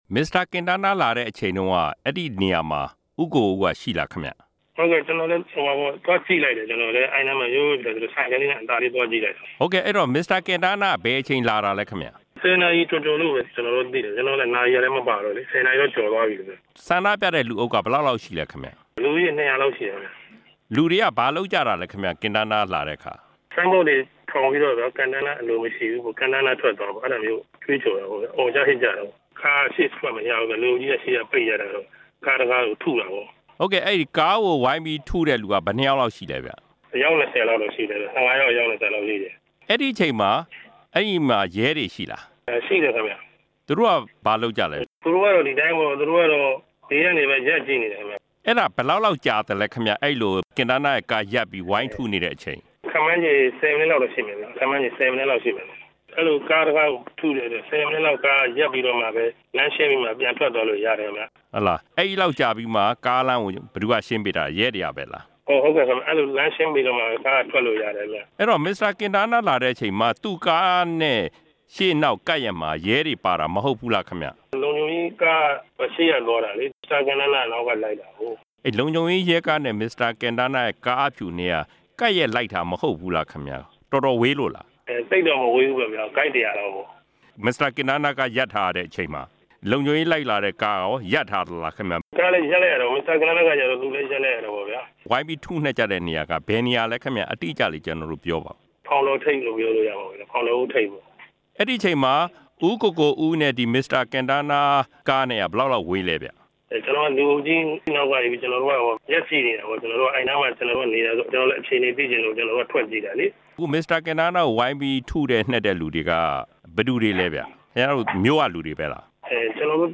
မိတ္ထီလာမြို့ခံ မျက်မြင်တစ်ဦးနဲ့ ဆက်သွယ်မေးမြန်းချက်